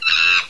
CHIMP1.wav